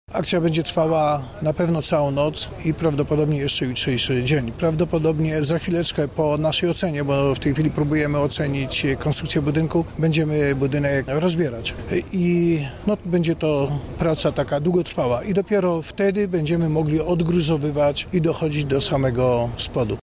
Zawaleniu uległ jeden z czterech segmentów kamienicy i zostanie on rozebrany – dodaje komendant: